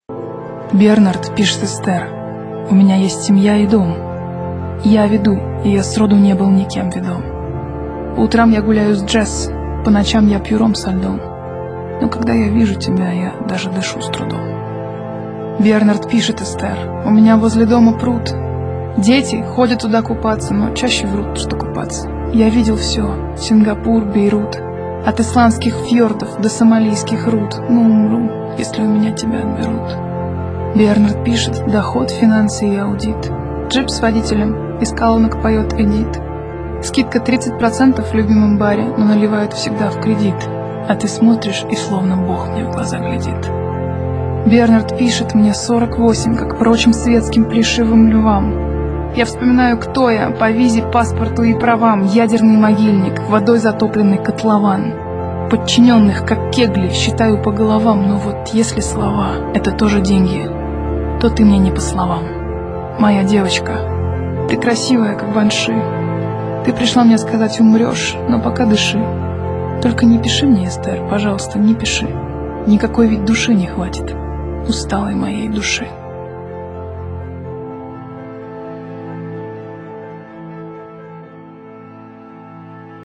2. «Полозкова – Бернард пишет Эстер (+ музыка)» /
Bernard-pishet-Ester-muzyka-stih-club-ru.mp3